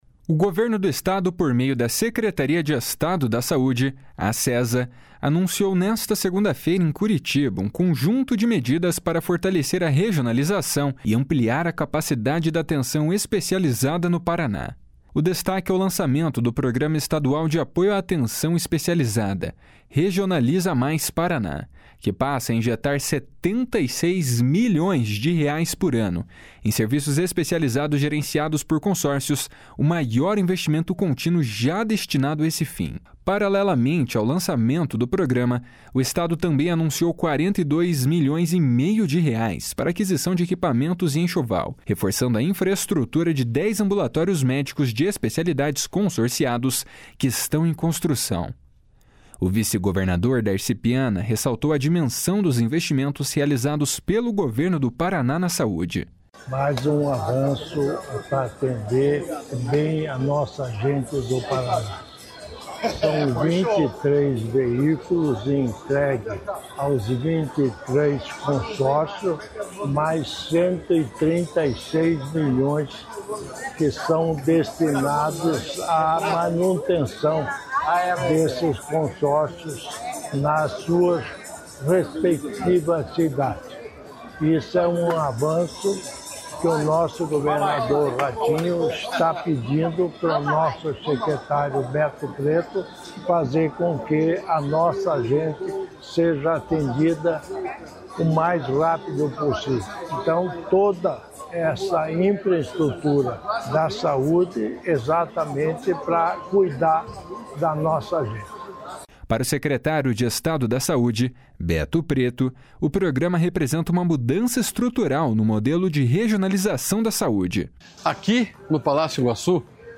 O vice-governador Darci Piana ressaltou a dimensão dos investimentos realizados pelo Governo do Paraná na saúde.
Para o secretário de Estado da Saúde, Beto Preto, o programa representa uma mudança estrutural no modelo de regionalização da saúde.